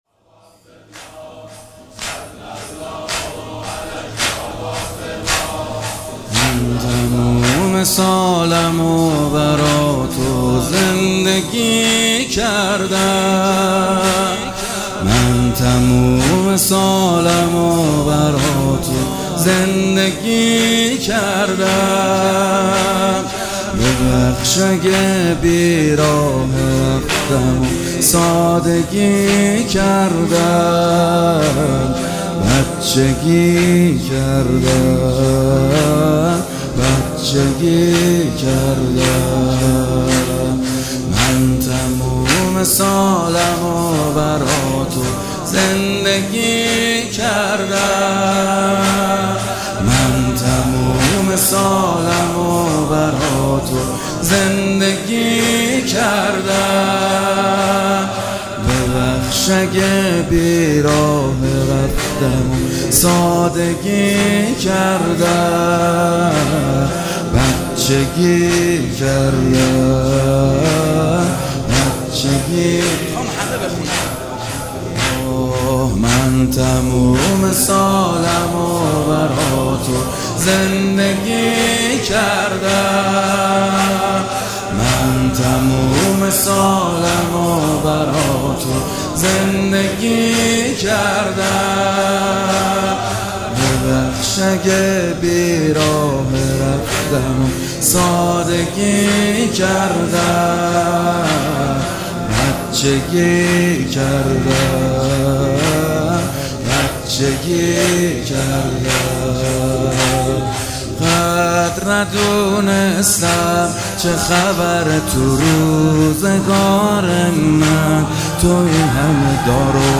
مداحی به سبک زمینه اجرا شده است.